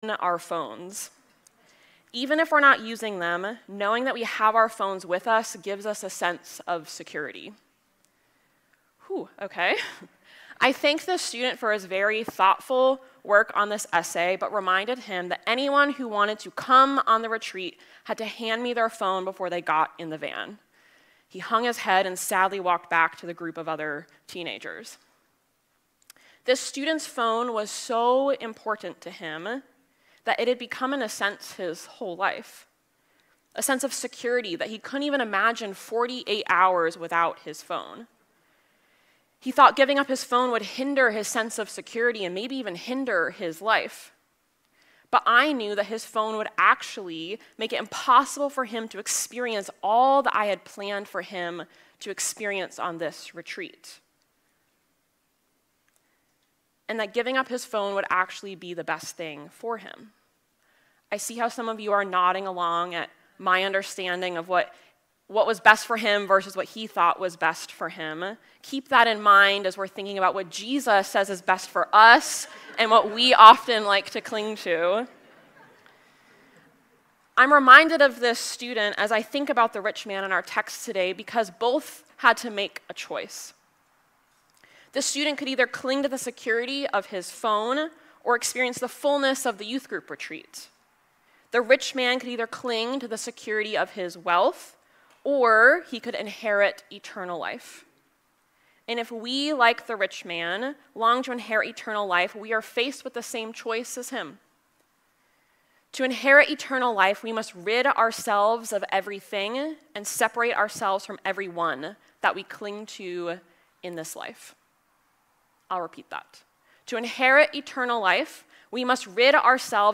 Sermons | Faith Covenant Church
(We apologize that the first few minutes are cut off!)